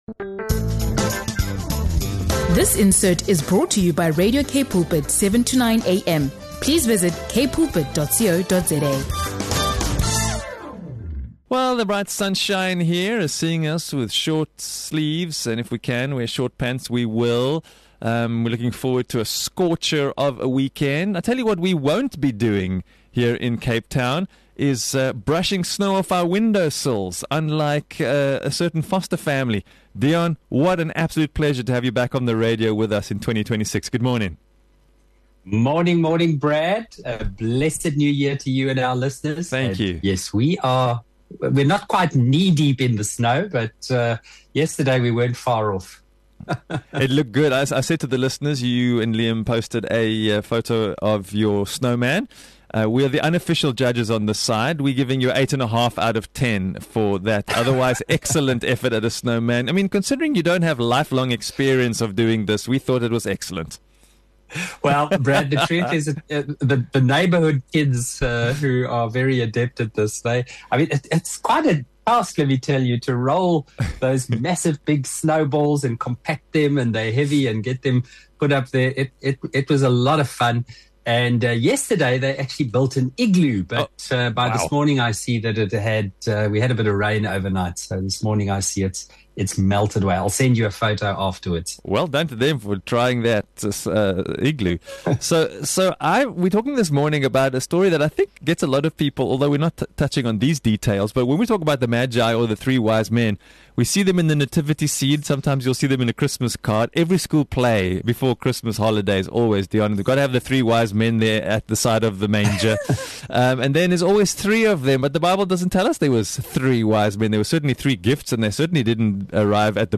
Moving beyond Christmas traditions, they explore what Scripture really says about the Magi, the journey to Bethlehem, and the powerful contrast between earthly power and the humble kingdom of God. Broadcast across continents—from sunny Cape Town to snowy Amsterdam—this episode reflects on spiritual discernment, holy attention, and what it means to follow the light of Christ into a new year.